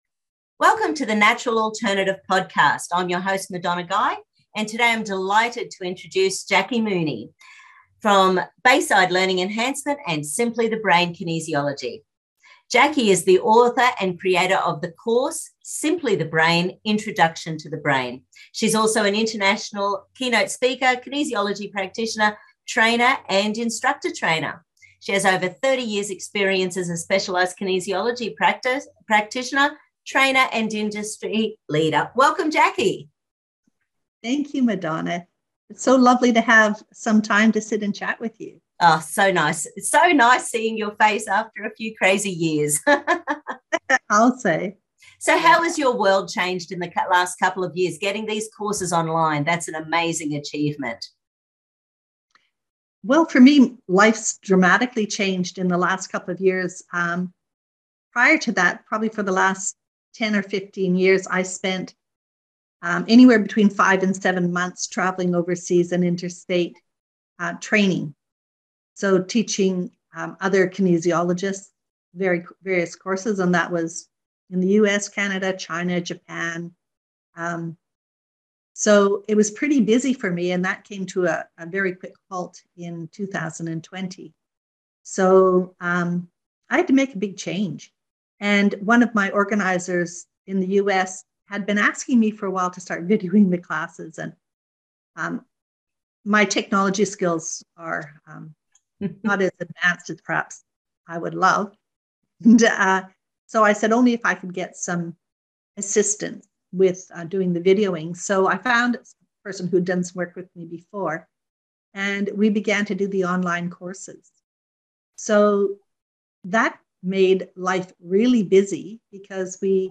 Complete Interview.